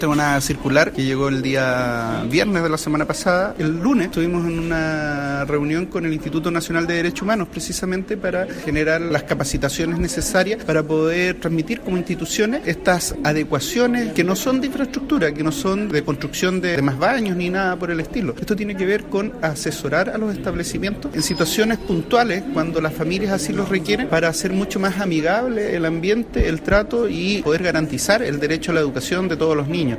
Es por ello que en el marco de la cuenta pública del Seremi de Educación de la región de Los Lagos, Pablo Baeza, comentó que tras una reunión con el Instituto Nacional de Derechos Humanos se llegó al consenso de que esto debe ser tratado a través de cursos y talleres de orientación tanto a profesores como a los apoderados.